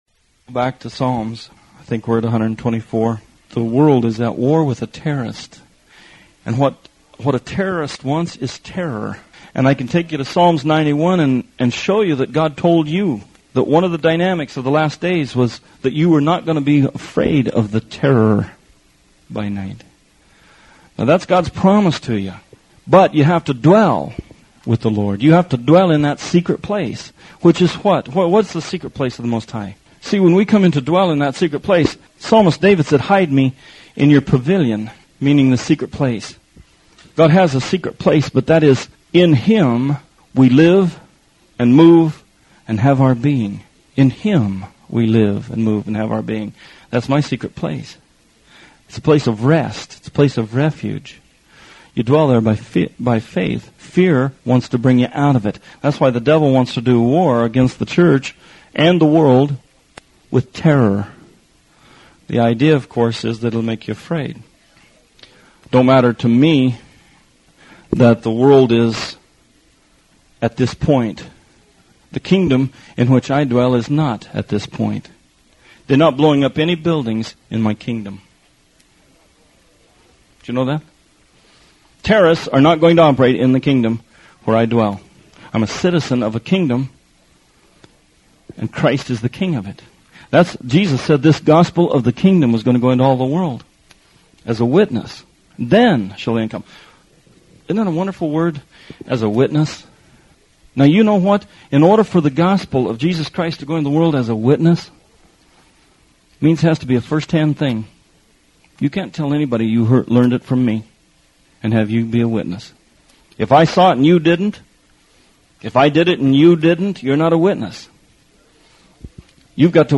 Songs of Degrees: This is a sermon series about Psalms 120 through 134.